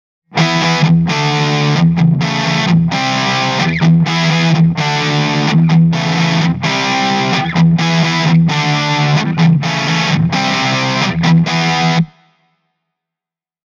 LES PAUL SÄRÖLLÄ
the-toob-r-e28093-les-paul-overdrive.mp3